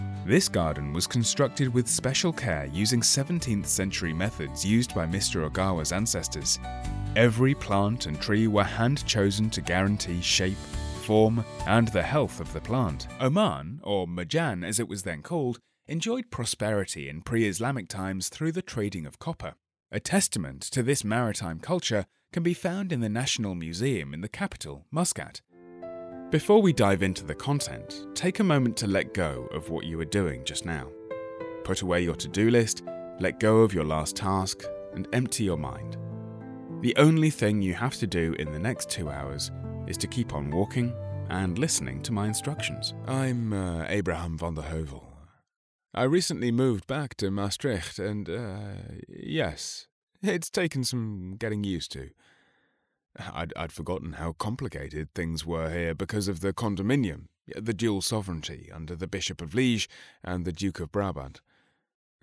Anglais (Britannique)
Naturelle, Fiable, Chaude, Amicale, Corporative
Vidéo explicative